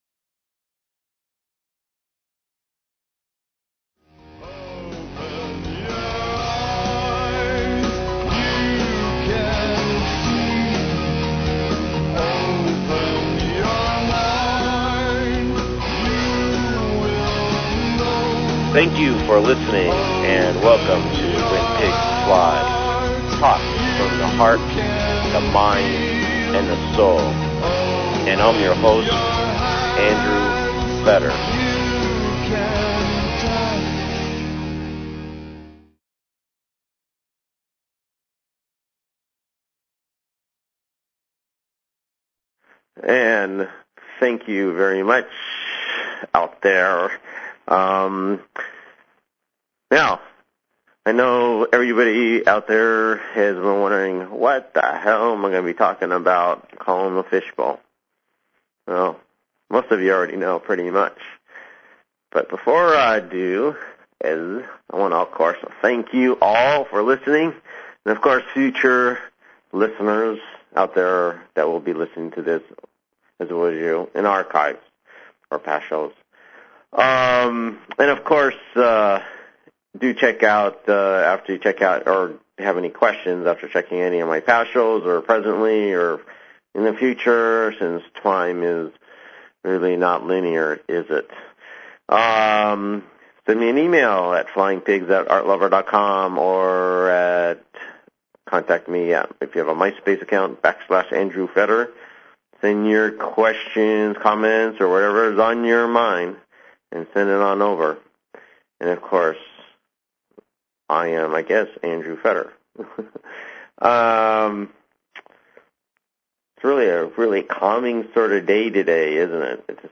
Talk Show Episode, Audio Podcast, When_Pigs_Fly and Courtesy of BBS Radio on , show guests , about , categorized as
Show Headline When_Pigs_Fly Show Sub Headline Courtesy of BBS Radio Due to some technical difficulties: this weeks taping sounds like I was in a fishbowl....